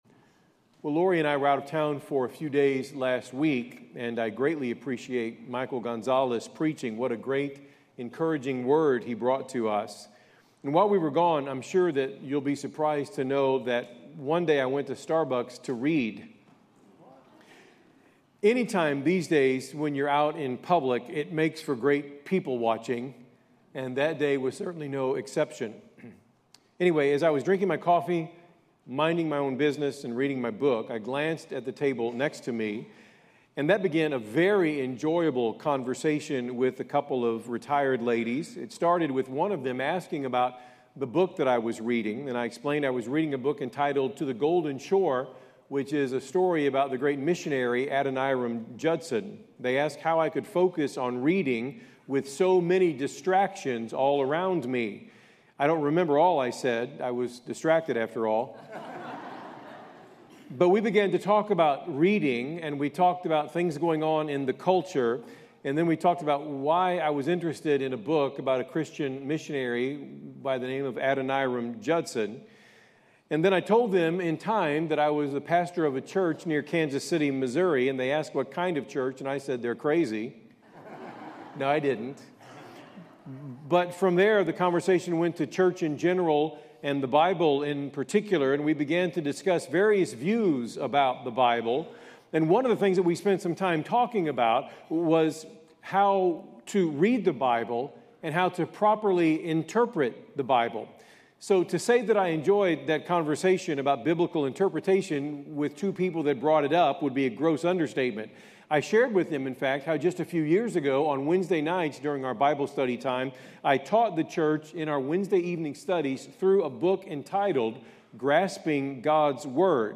First Baptist Church Kearney MO - Sermon, A Lamp Shining in a Dark Place (Part 10), June 29, 2025